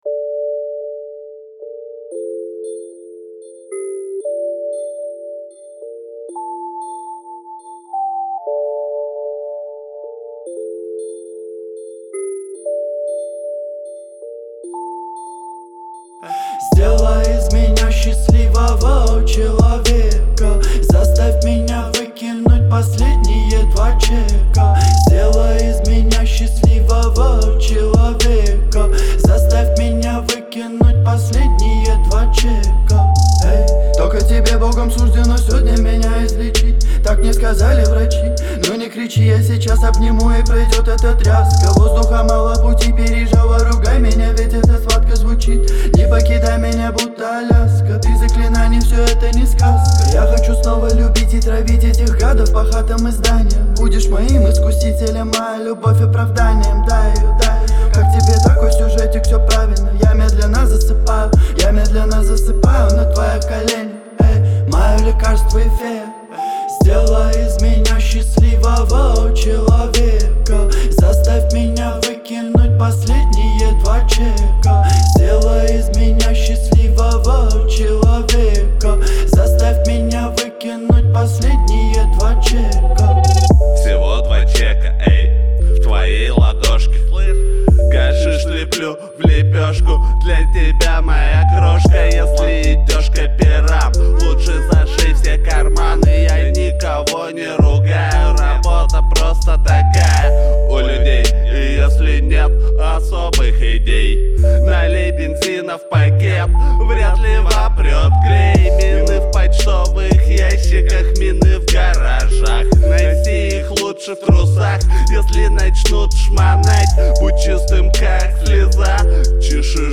это яркая композиция в жанре хип-хоп